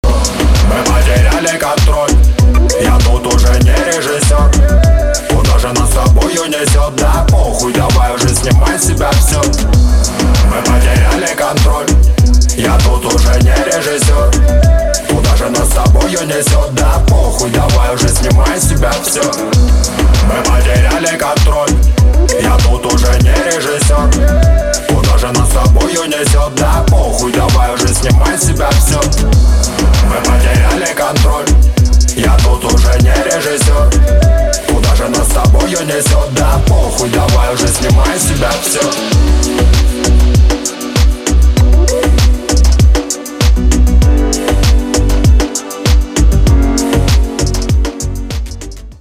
• Качество: 320, Stereo
Хип-хоп
русский рэп